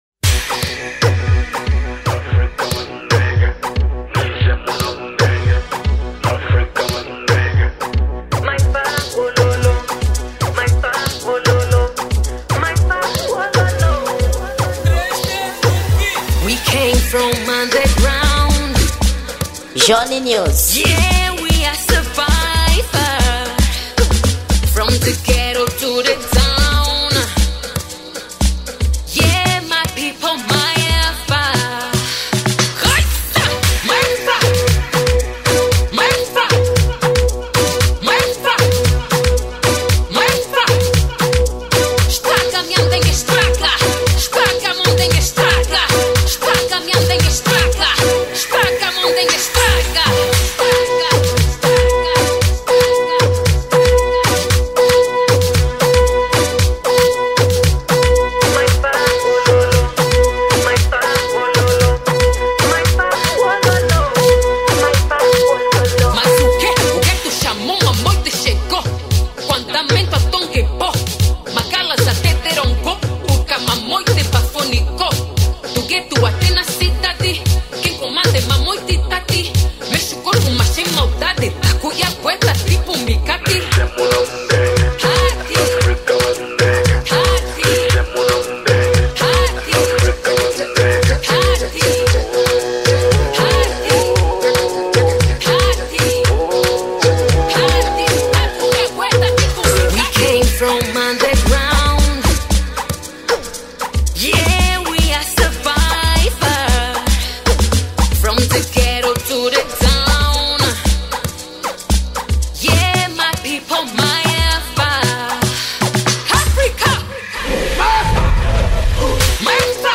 Gênero: Amapiano